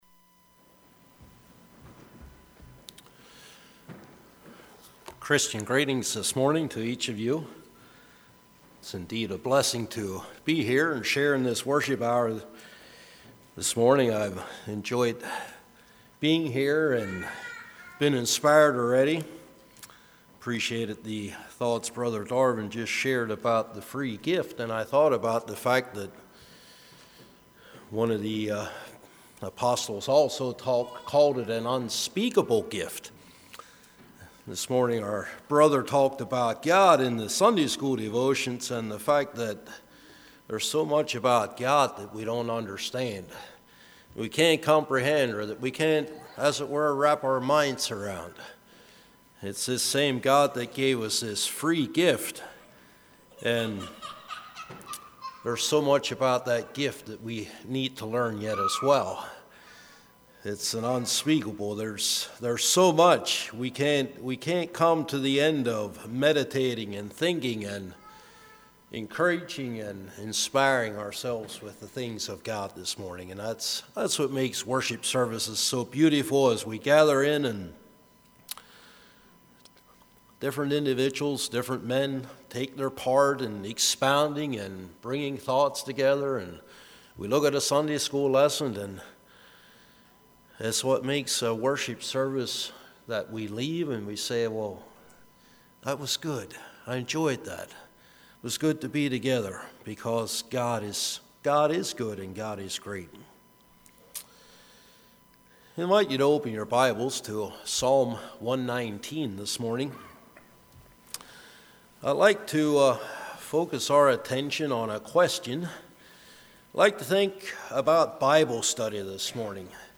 2017 Sermon ID